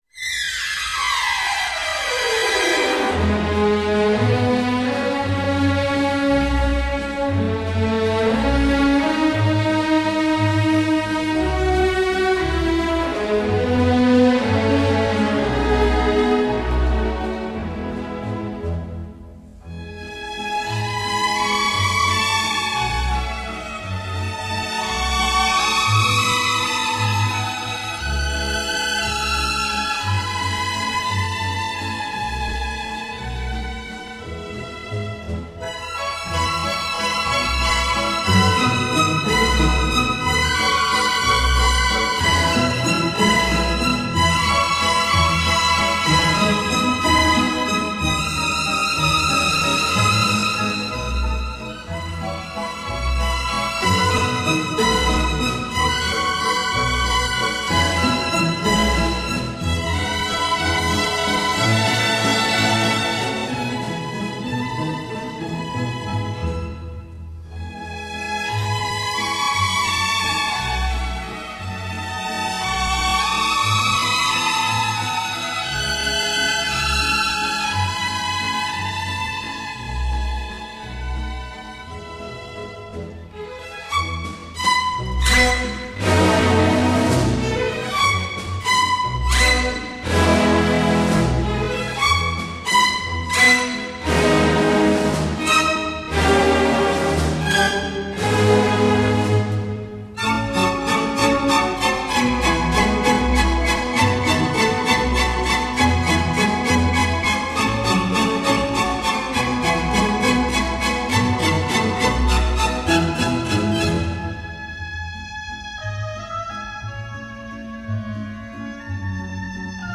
02 Viennese Waltz